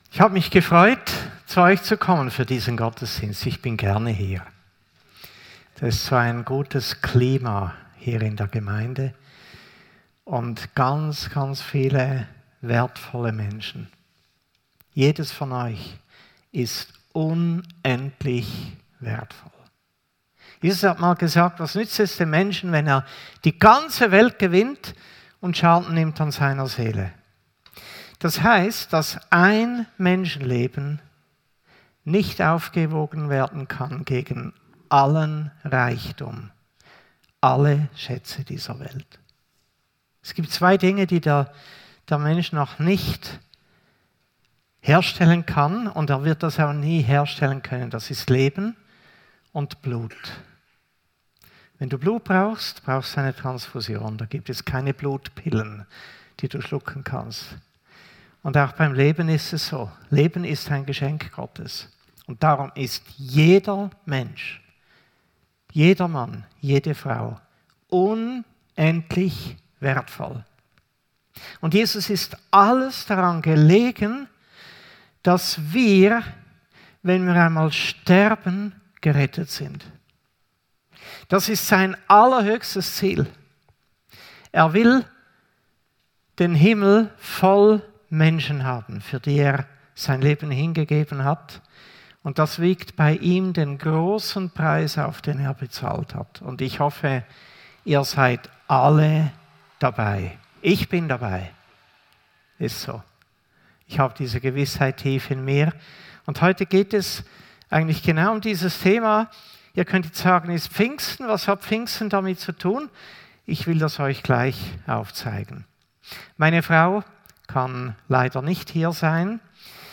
Predigt 19. Mai 2024